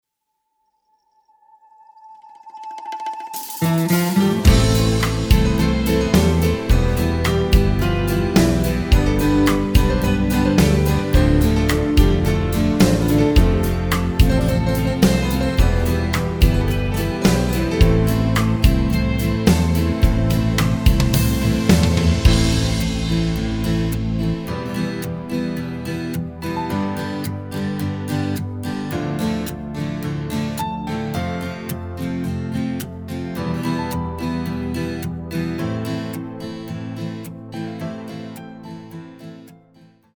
פלייבק איכותי – תואם מקור